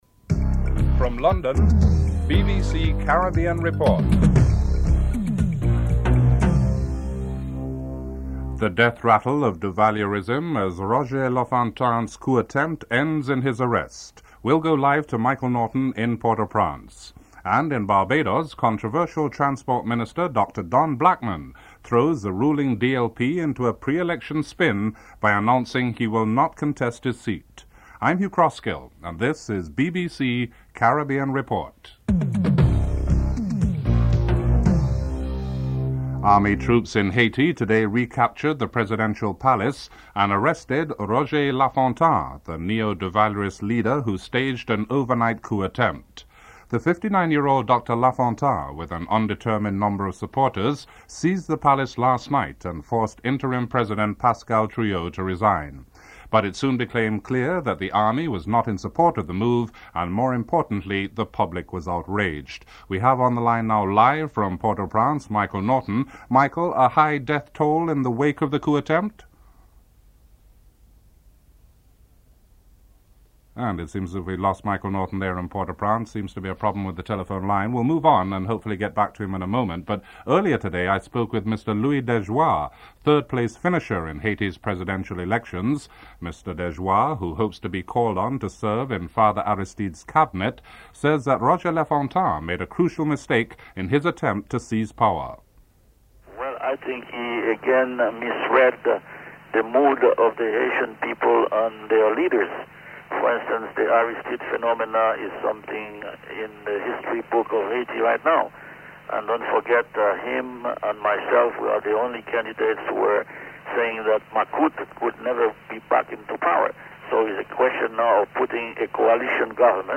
1. Headlines (00:00-01:21)